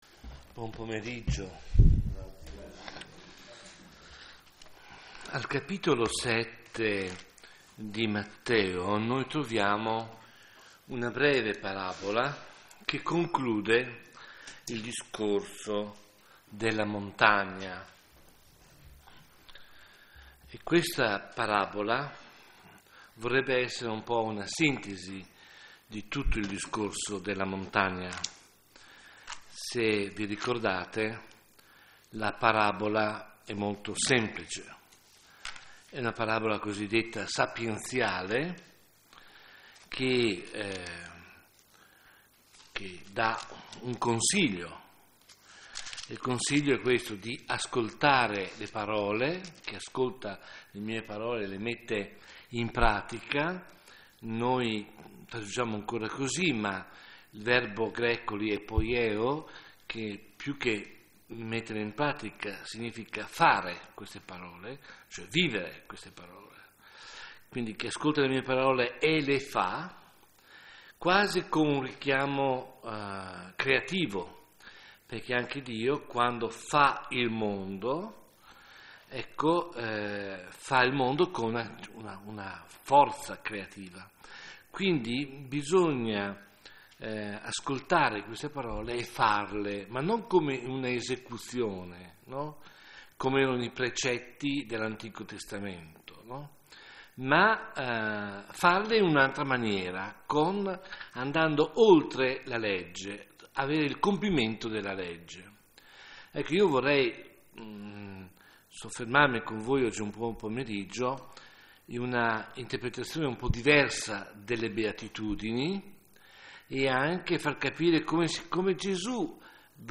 Serie: Meditazione